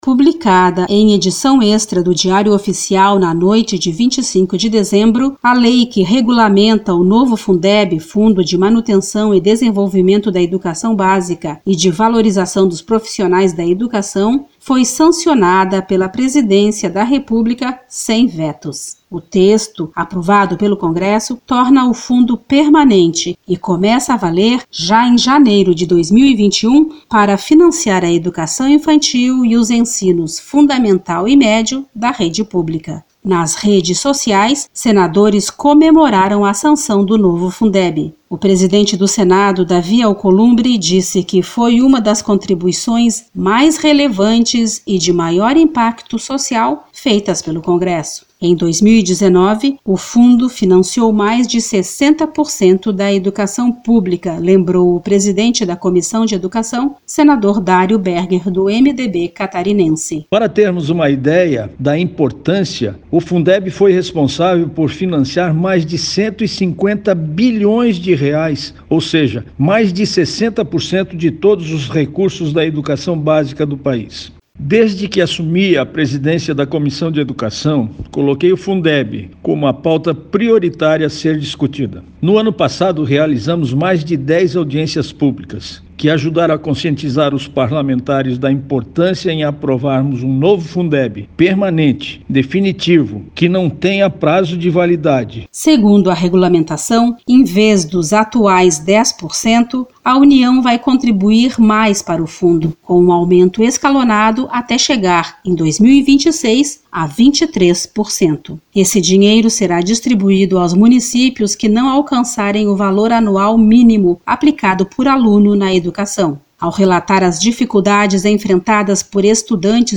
Mais informações na reportagem